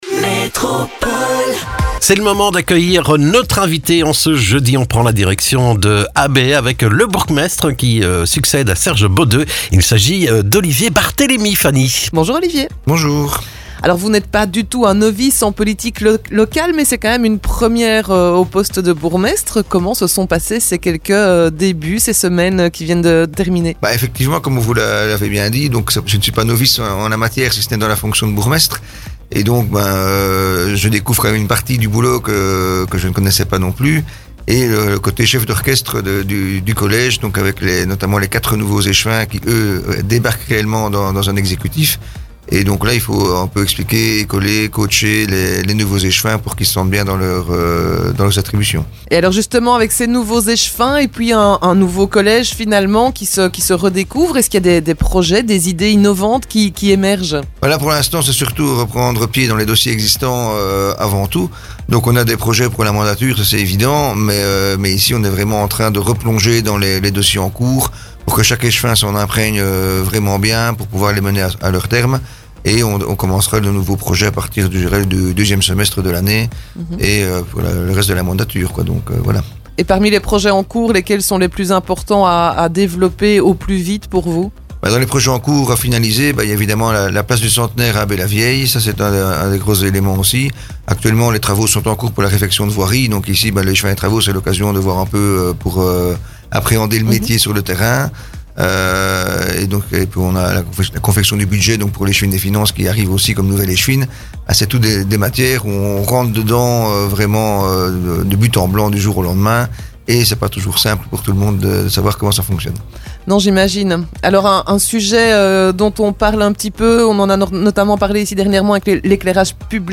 Nous avons rencontré le nouveau bourgmestre d'Habay, Olivier Barthélémy qui fait le point avec nous sur les dossiers qui concernent sa commune comme le sentiment d'insécurité évoqué par de nombreux citoyens.